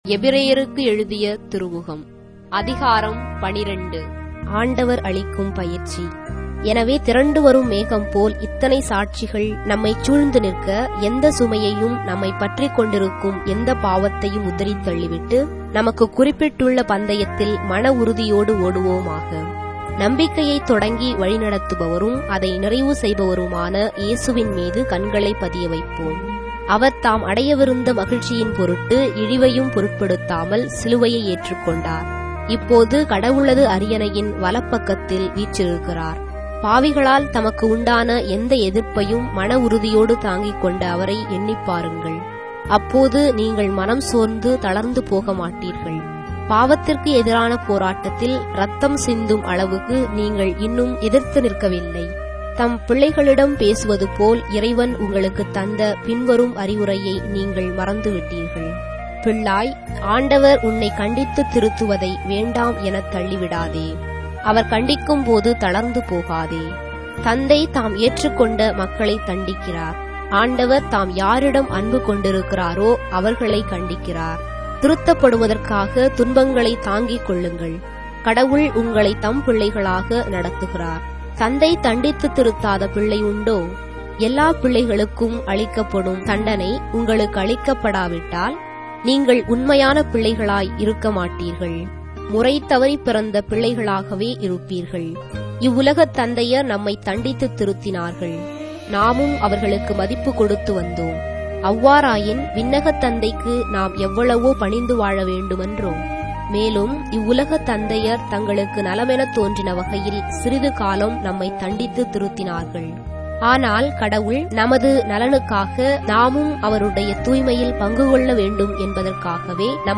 Tamil Audio Bible - Hebrews 3 in Ecta bible version